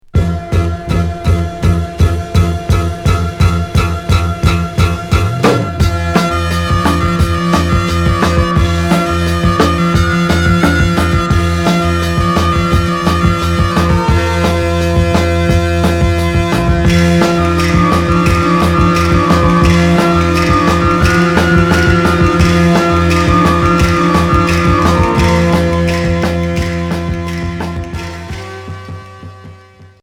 Pop progressif Troisième 45t retour à l'accueil